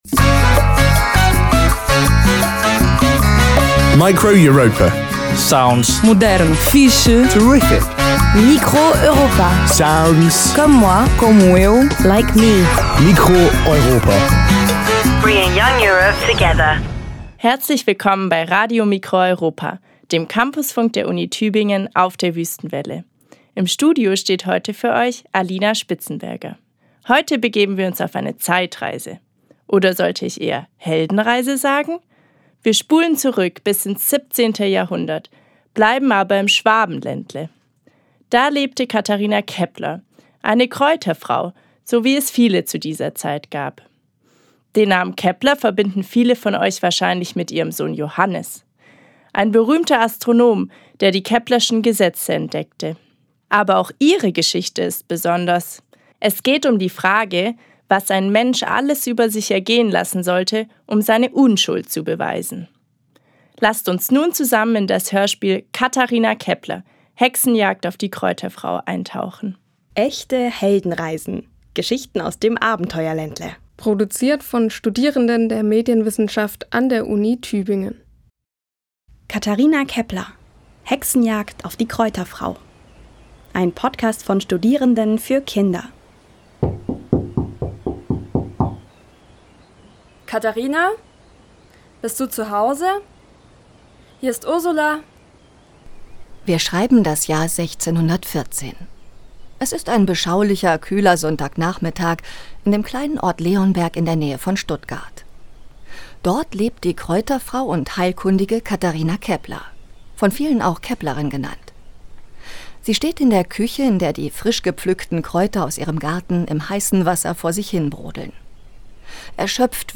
Kinderhörspiel: Katharina Kepler – Hexenjagd auf die Kräuterfrau
Live-Aufzeichnung, geschnitten